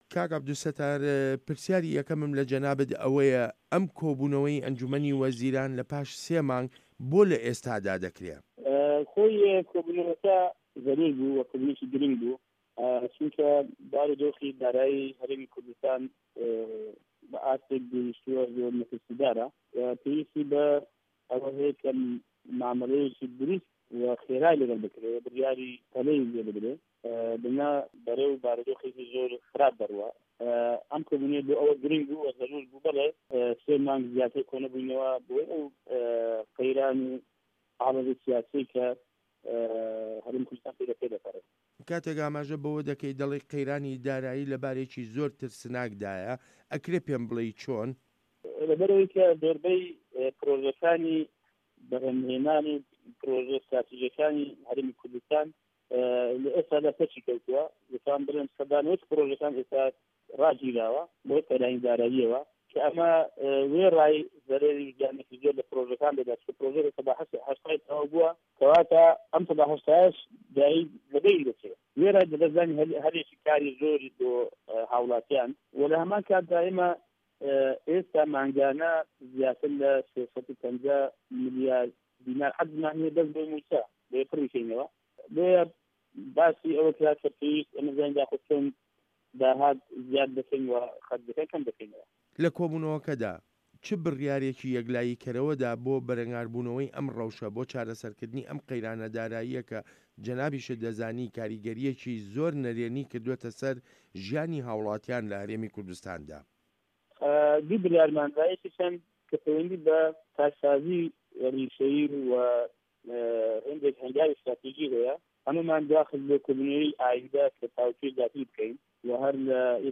وتووێژ لەگەڵ عەبدولستار مەجید